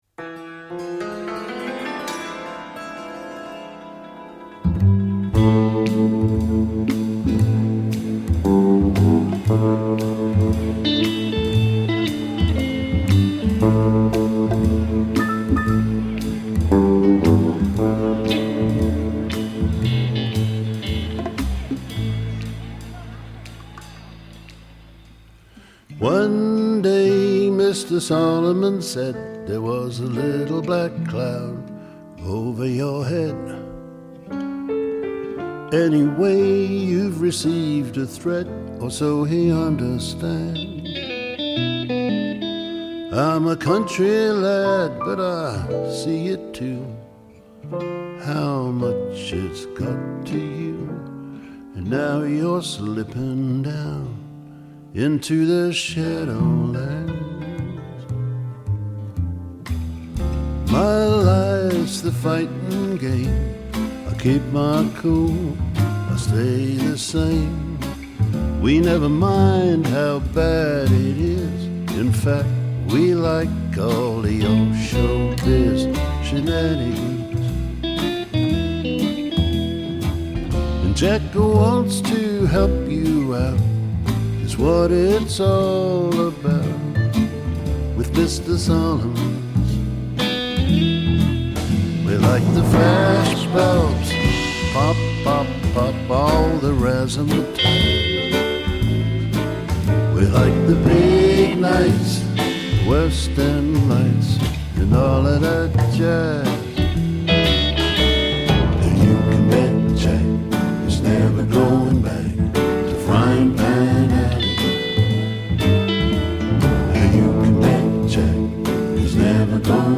Folk Rock, Blues